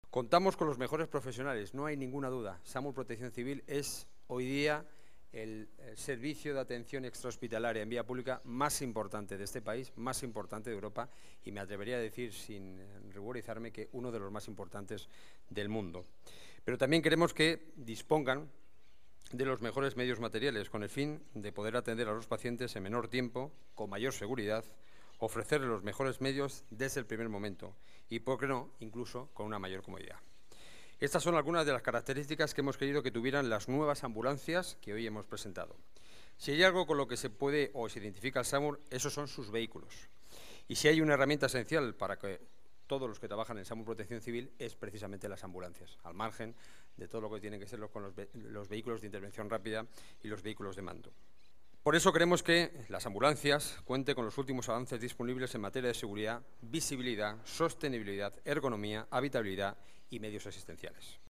Nueva ventana:Declaraciones delegado Seguridad y Emergencias, Enrique Núñez: nuevas ambulancias SAMUR, mejores medios